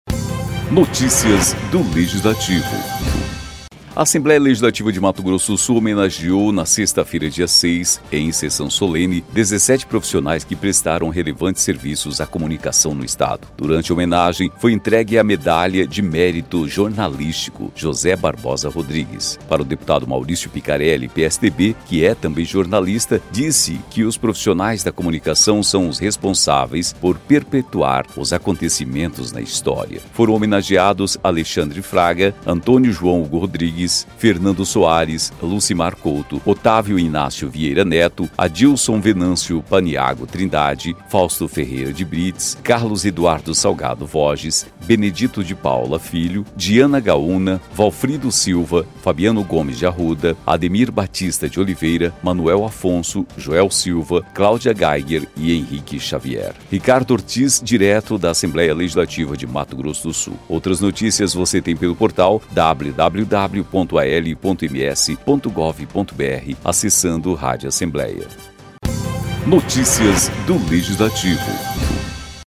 Em sessão solene, ALMS homenageia profissionais de comunicação